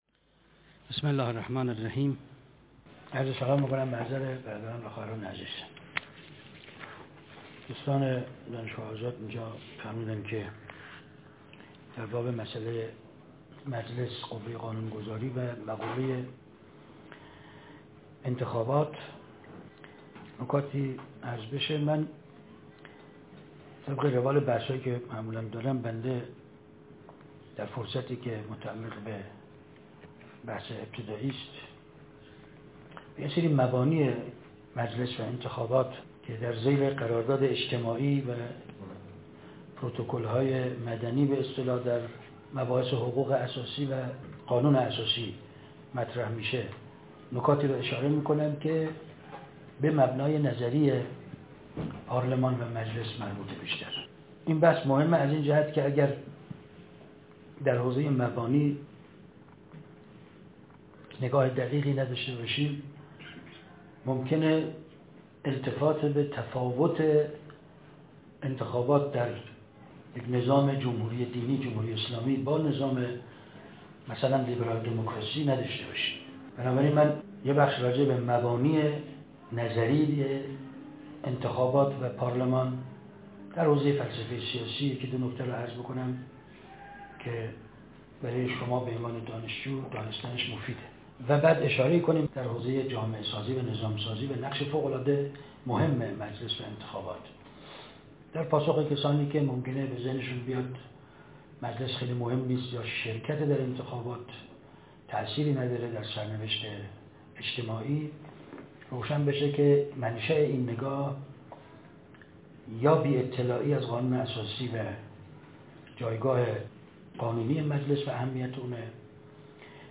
نشست ('مردم سالاری' با کدام منطق؟) _ ۹۸